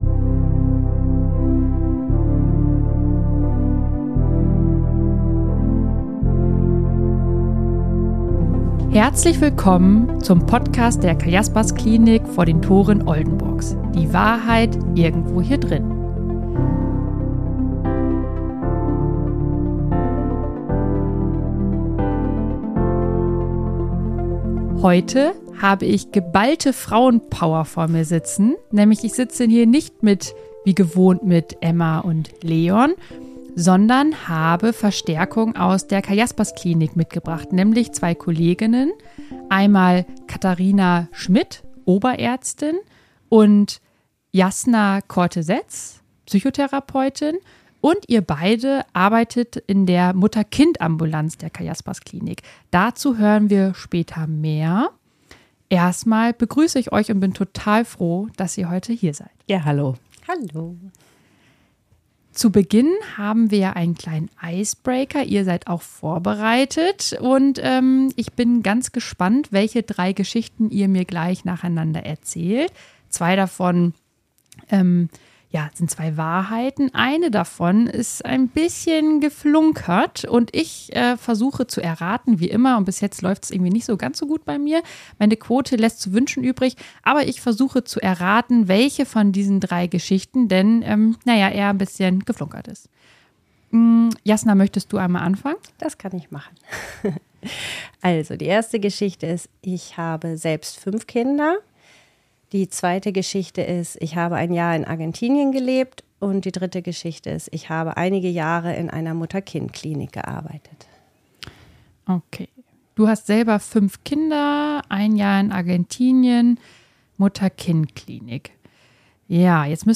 #19 MUTTER-KIND-AMBULANZ – Experten-Talk ~ Die Wahrheit Irgendwo Hier Drinnen Podcast